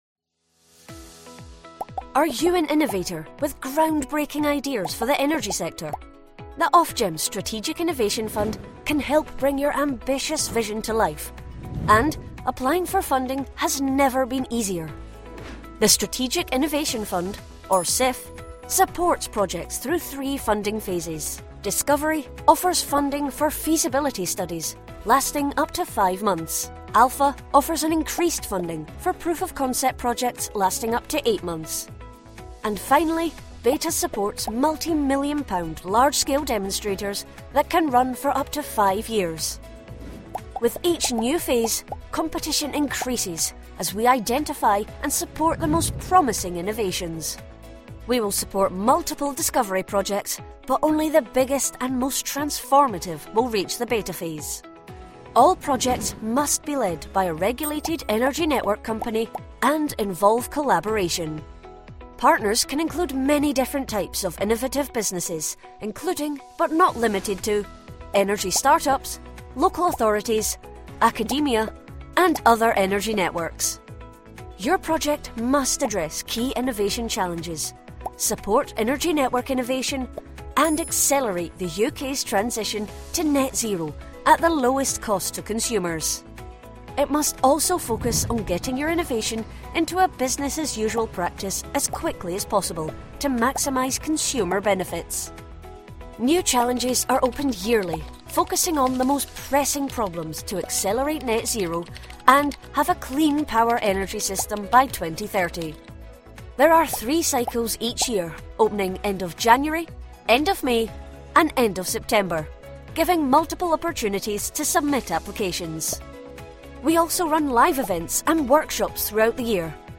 Inglês (escocês)
Vídeos Explicativos